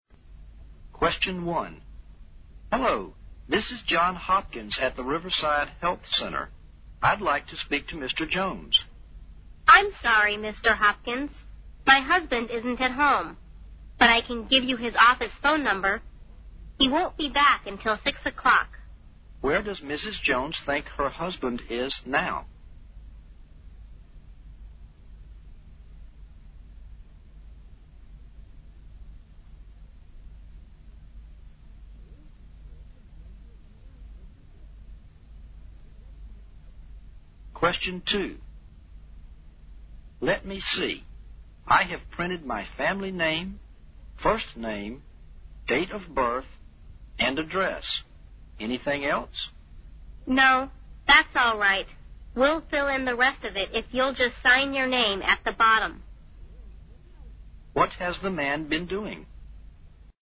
新编大学英语四级听力短对话每日2题 第174期MP3音频下载,本期为1996年1月四级听力真题。